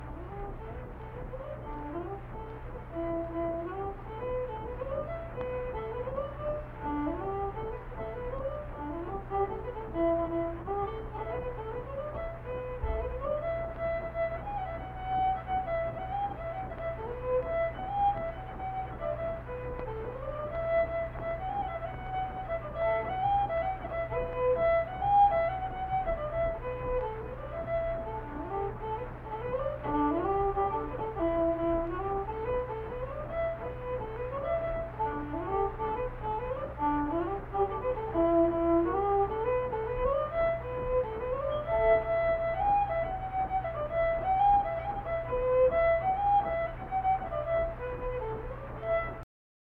Unaccompanied fiddle music performance
Verse-refrain 2(2).
Instrumental Music
Fiddle